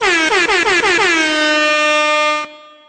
Boop Sound Button: Meme Soundboard Unblocked